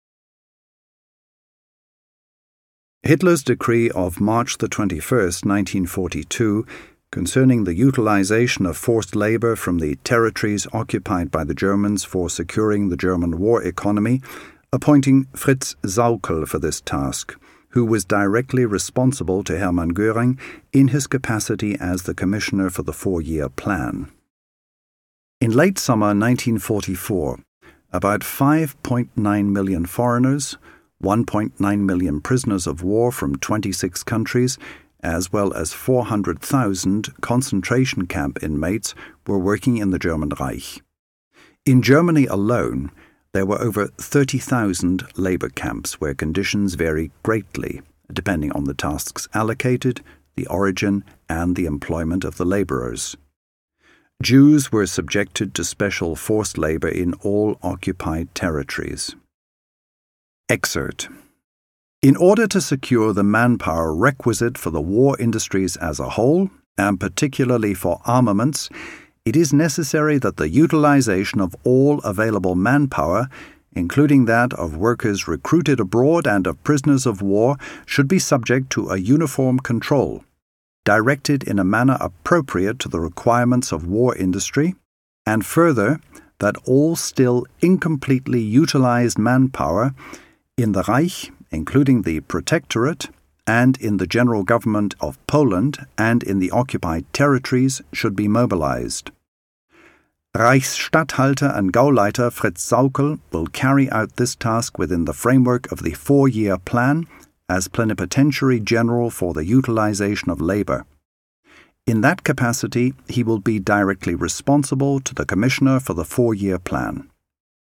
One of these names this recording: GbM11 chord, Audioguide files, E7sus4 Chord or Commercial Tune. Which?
Audioguide files